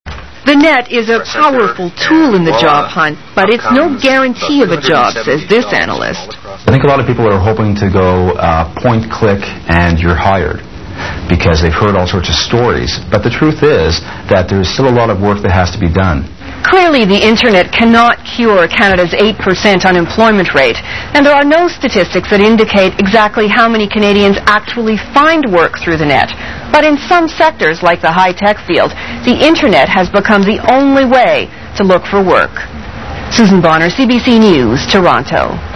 Toronto news